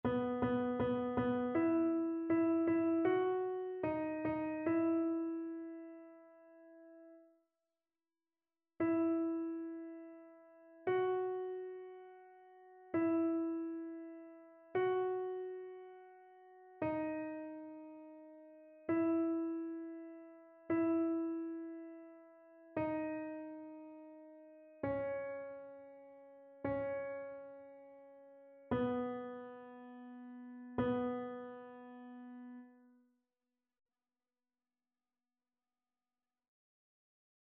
AltoTénorBasse
annee-c-temps-du-careme-4e-dimanche-psaume-33-alto.mp3